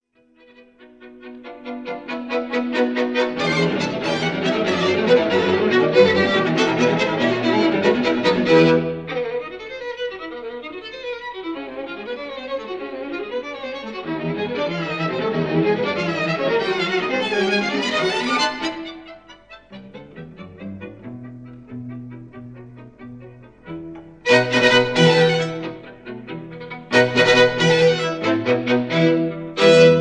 This is a 1950 recording of the Prestissimo